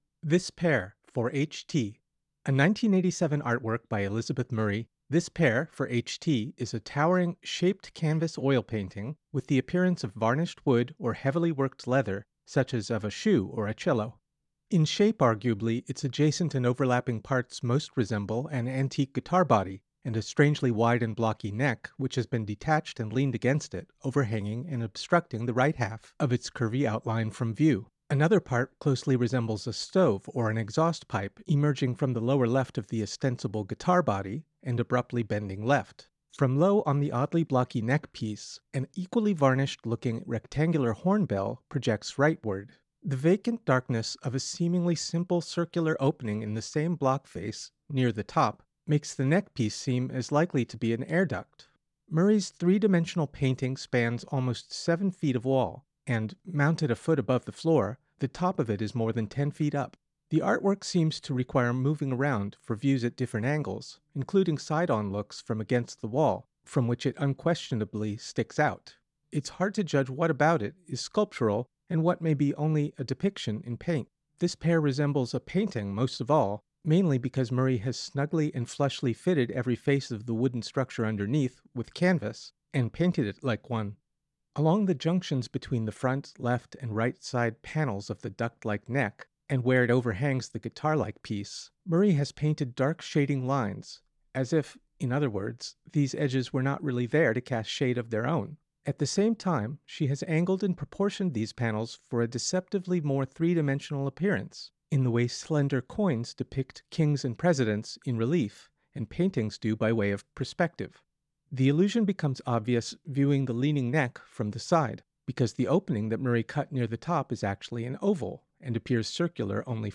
Audio Description (02:45)